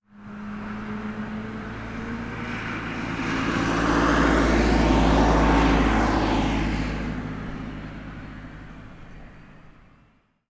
Coche pasando a velocidad normal 4
coche
Sonidos: Transportes